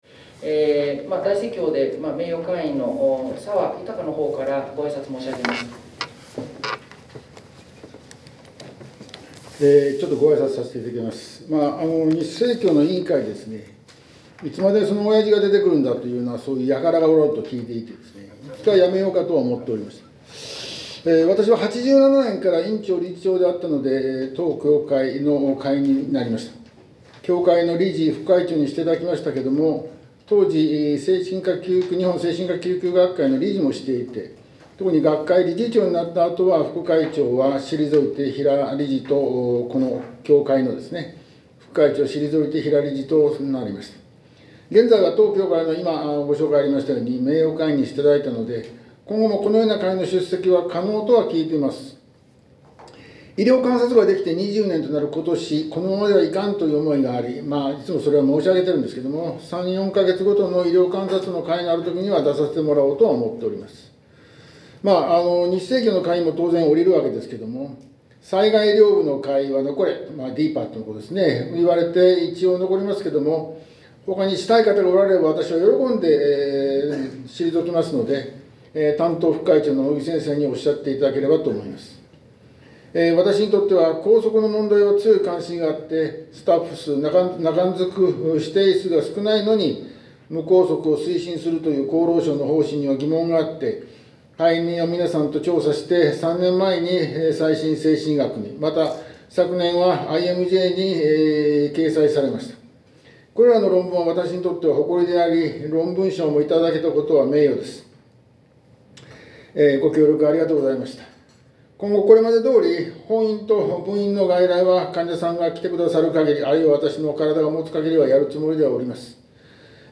大阪精神科病院協会会員交代挨拶（定例会議2025年5月7日）